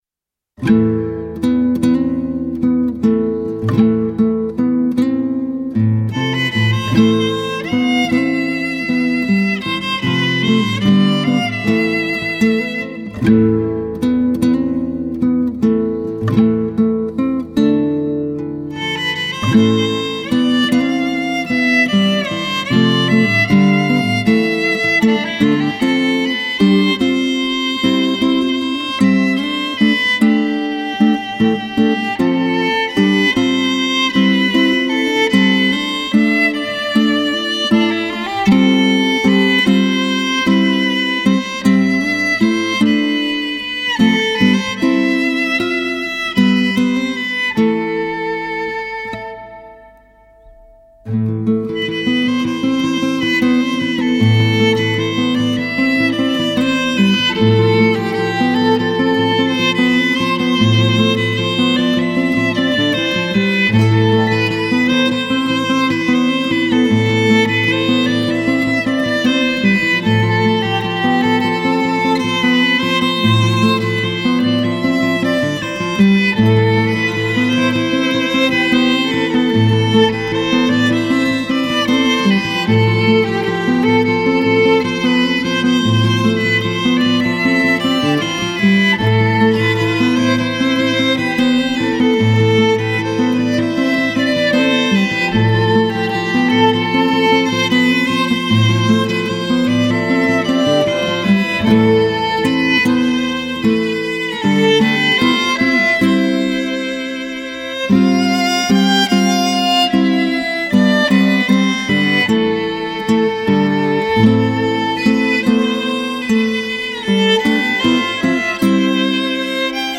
Žánr: World music/Ethno/Folk
housle
kytara